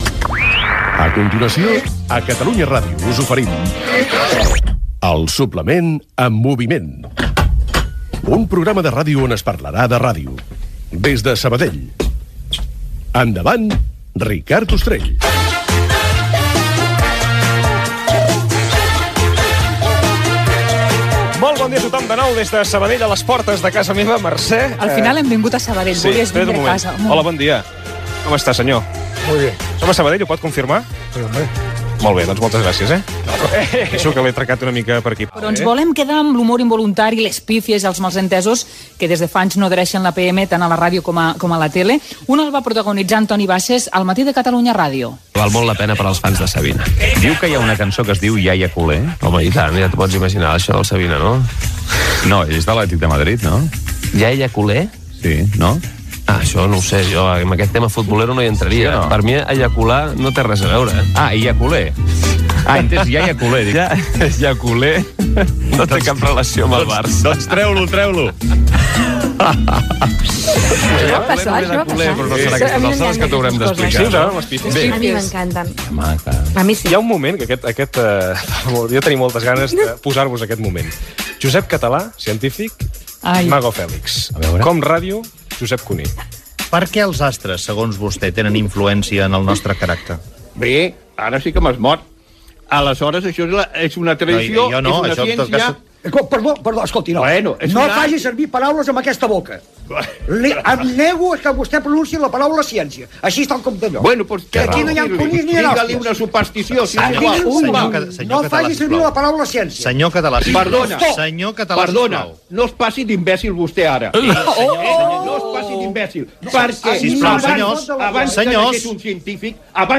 Especial Dia Mundial de la ràdio 2017 fet des de la casa de Ricard Ustrell. Repàs a alguns talls divertits.
Entreteniment